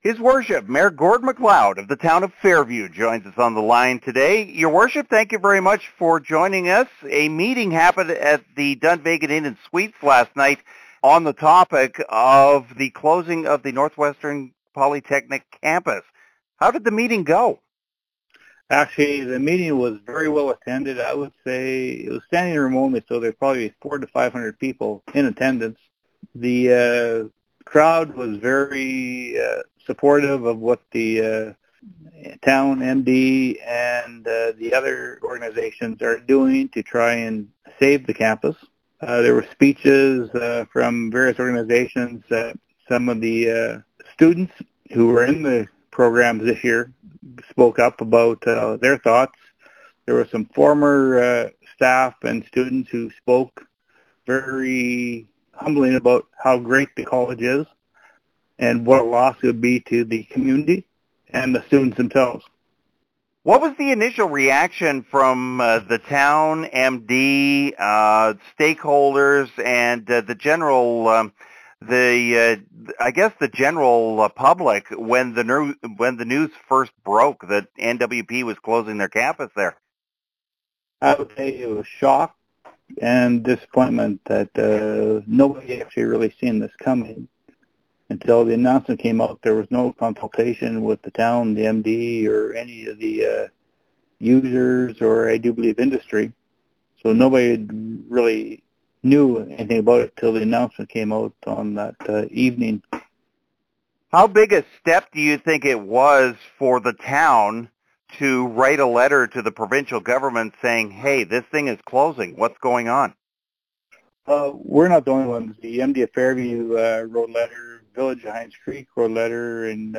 In the Meantime, His Worship, Mayor Gord MacLeod talks about the meeting, the enthusiasm, the next steps, and the optimism to keep the campus open.